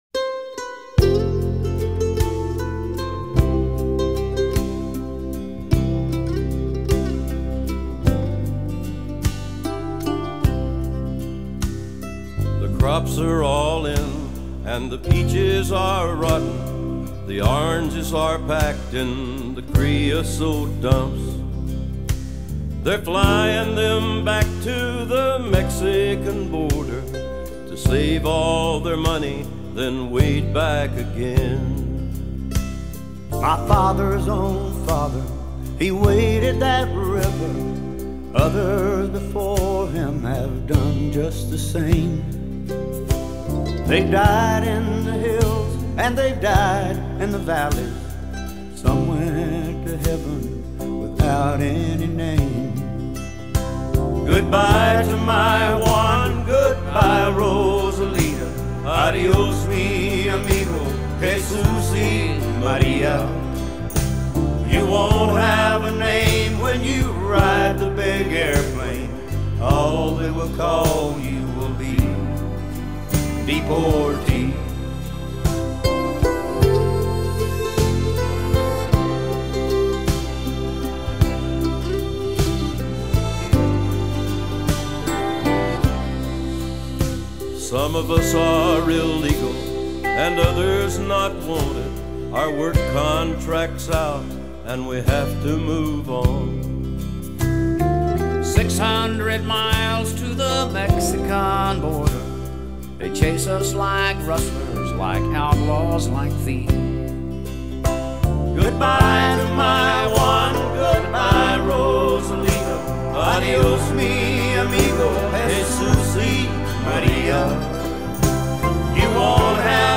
It arrives in the hands of an exceptional country quartet:
a poignant and swinging version, like horseback riding.